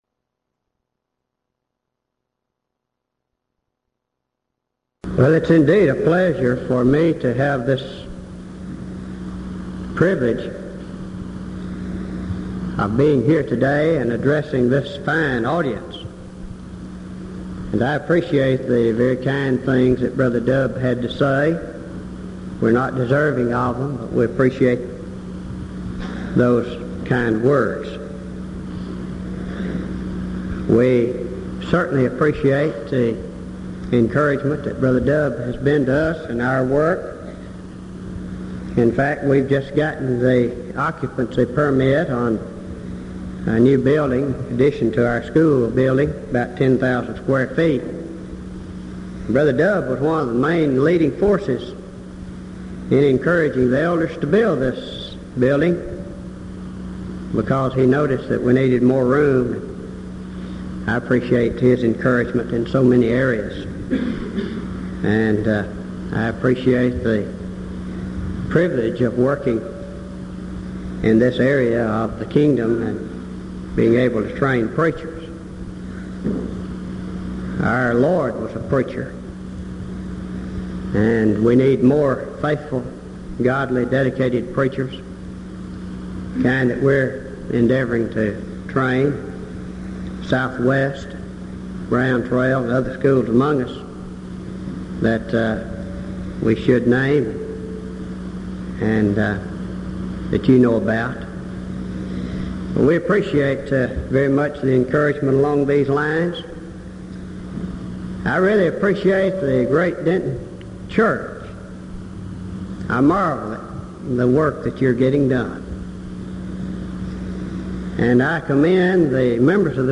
Event: 1987 Denton Lectures
lecture